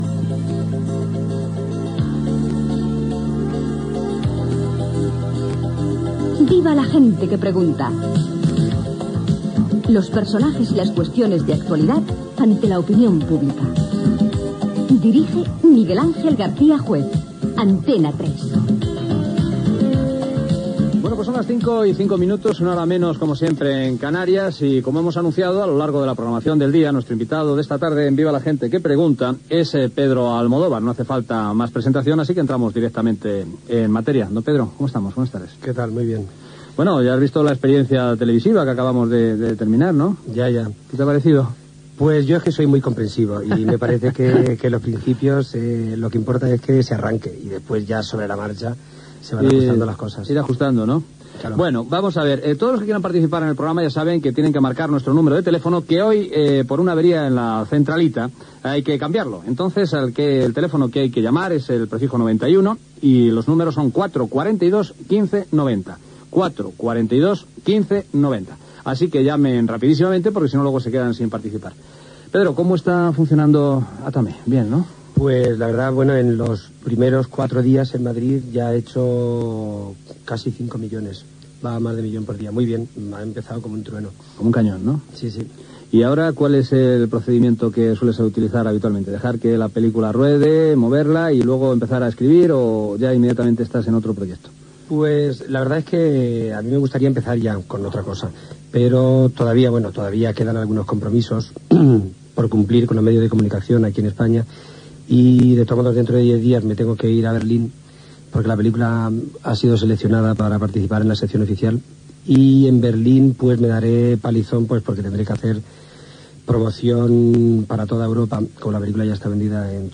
Careta del programa, hora, fragment d'una entrevista al director de cinema Pedro Almodóvar amb la participació dels oients. S'acabava d'estrenar "Átame".
Entreteniment